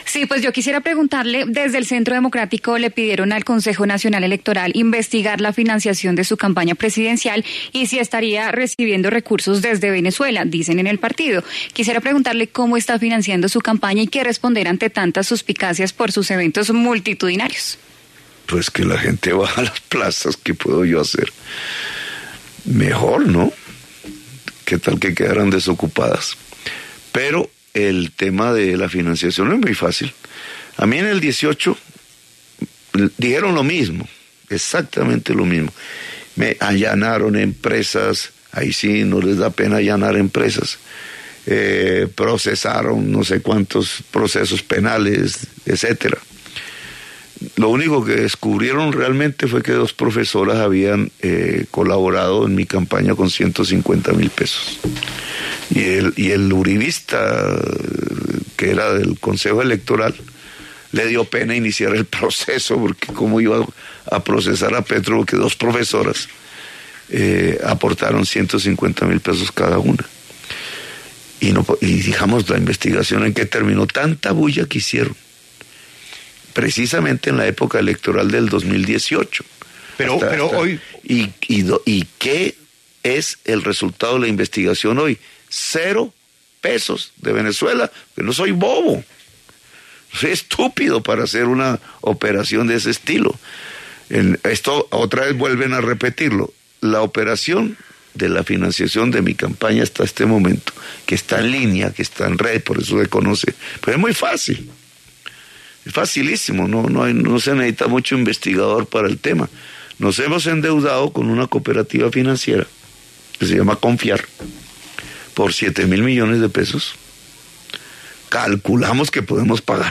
Gustavo Petro, precandidato del Pacto Histórico, habla en La W